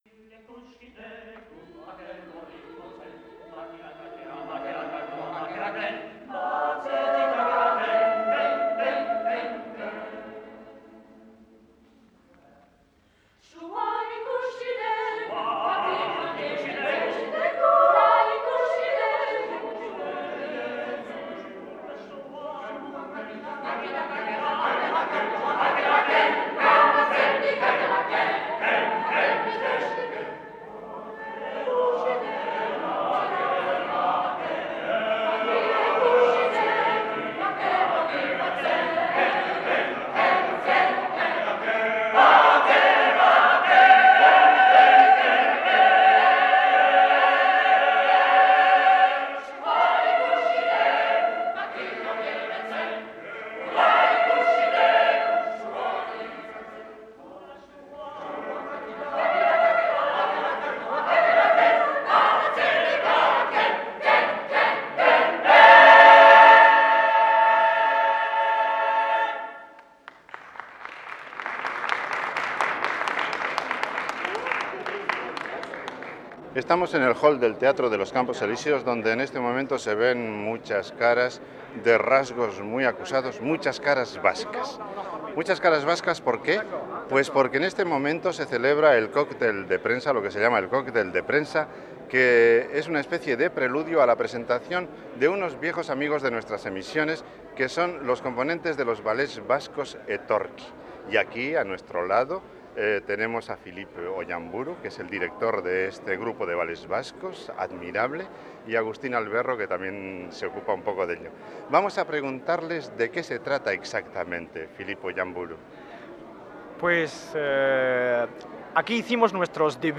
Hona hemen elkarrizketa Alakanteko Unibertsitateri esker.